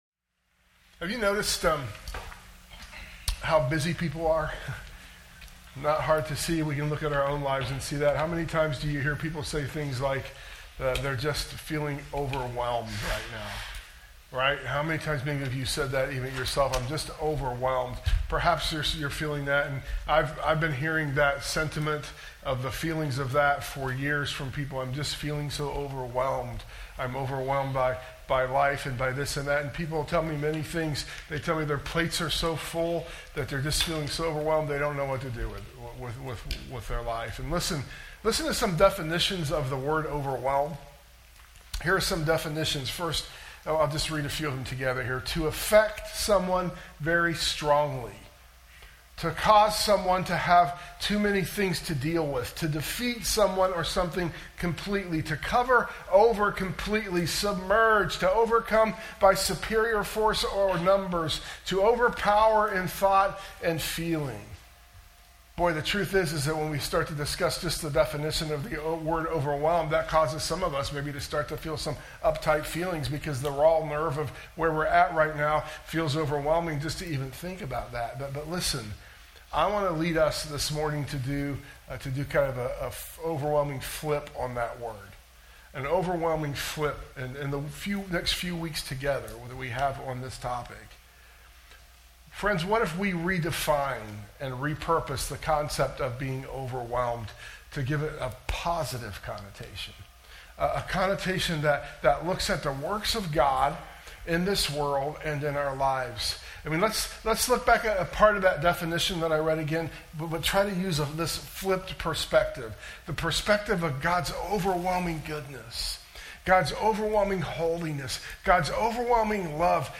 sermon_audio_mixdown_11_2_25.mp3